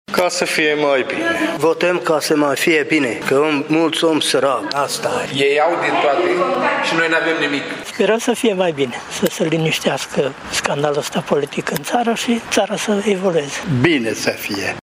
stiri-5-iunie-voxuri.mp3